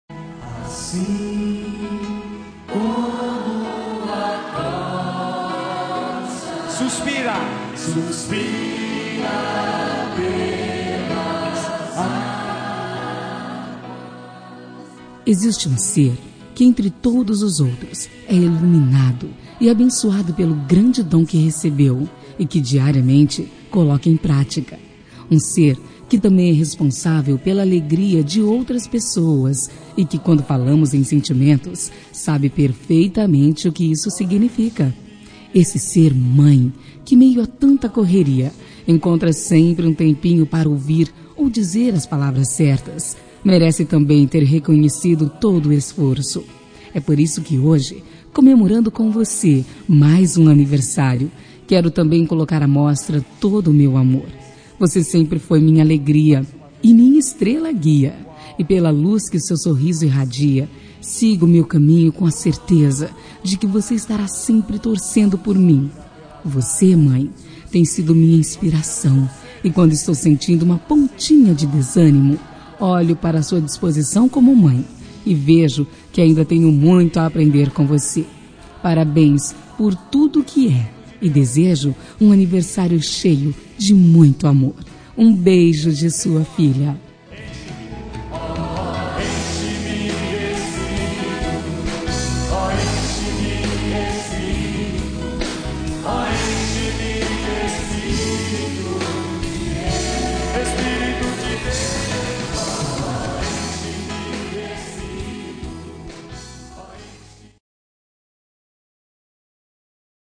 Telemensagem Aniversário de Mãe – Voz Feminina – Cód: 1413 Religiosa